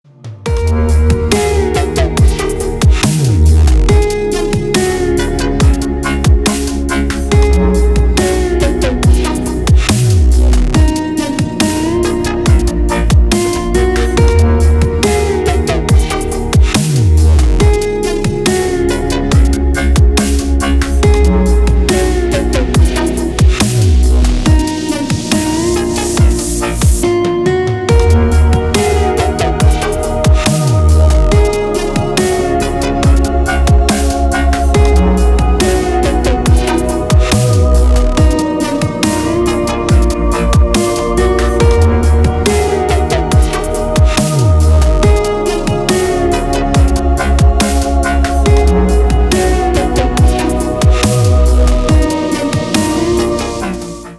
Спокойный DubStep на звонок
Dubstep рингтоны